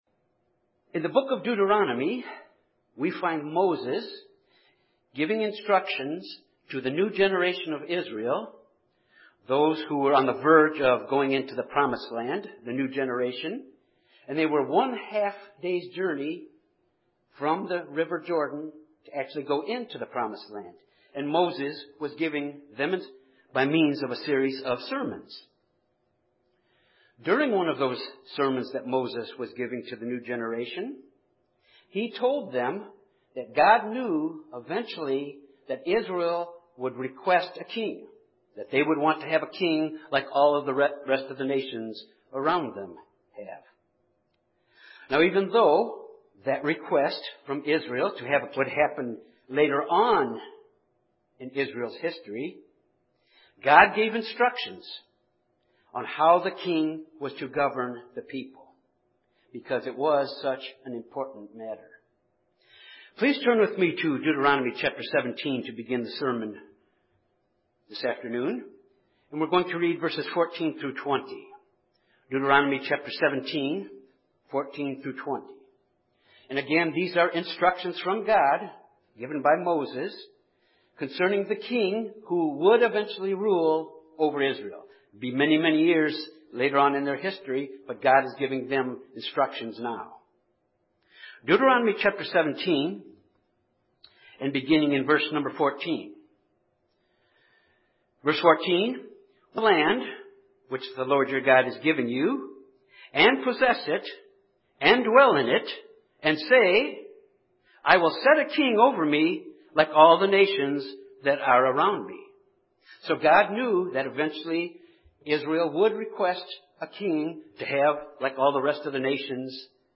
This sermon examines Josiah, the last good king of Judah and learn three lessons of godly zeal that Josiah displayed. This zeal that Josiah displayed and reformations he instituted foreshadowed the type of reforms that will be made when Jesus returns to the earth.